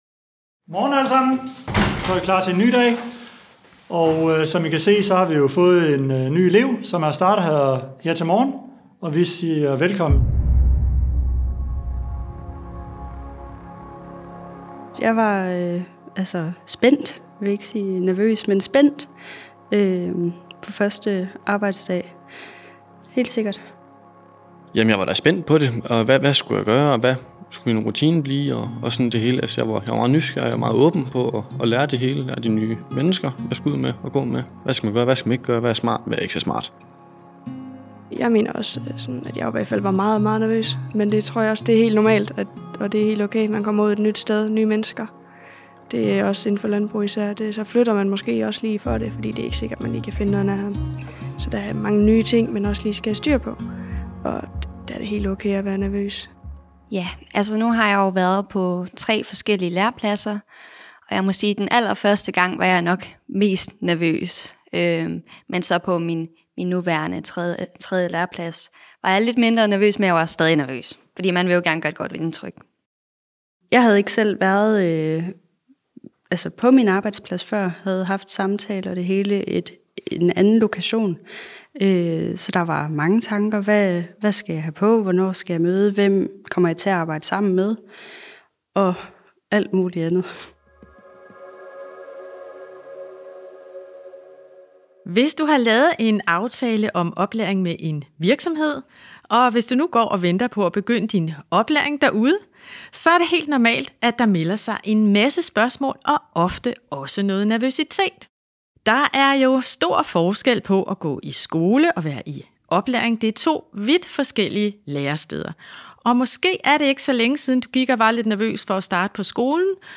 Du får erfaringer og gode råd fra et lærlingepanel, der deler deres oplevelser, og vi besøger en virksomhed, der har stor erfaring med at tage imod nye lærlinge.